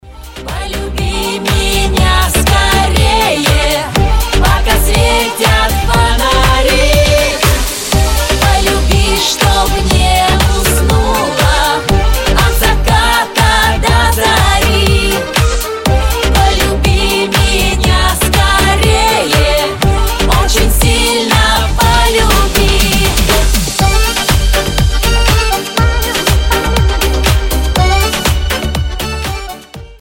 • Качество: 256, Stereo
веселые